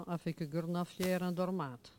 Localisation Perrier (Le)
Catégorie Locution